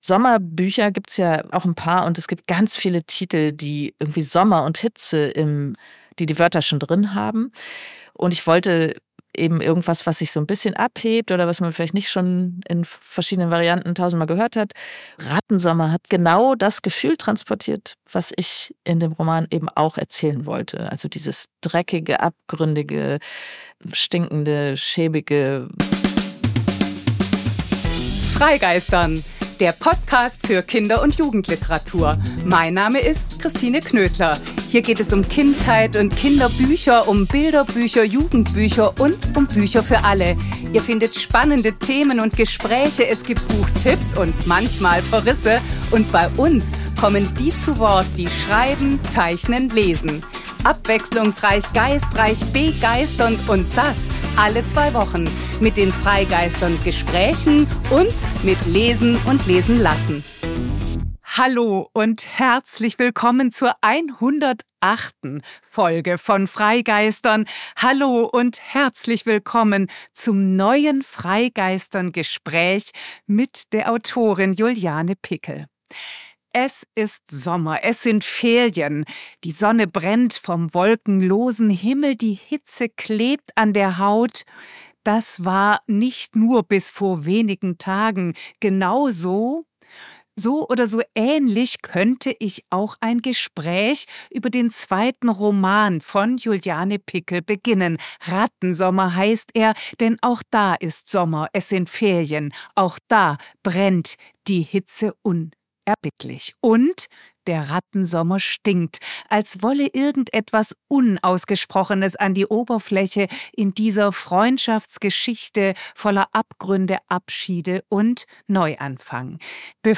Ein Gespräch übers Schreiben und über Schaffensprozesse, über Figuren, deren Beziehung und Verlorensein, über Schwimmbad-, Sommerbücher und über Schullektüren.